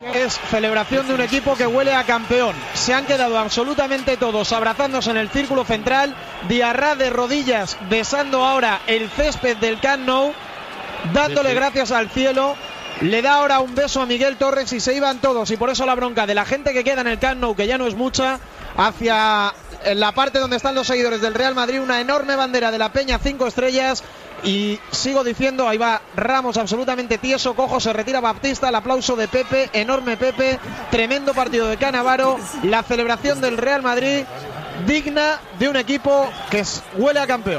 Narració del partit de la lliga masculina de primera divisió entre el Futbol Club Barcelona i el Real Madrid.
Esportiu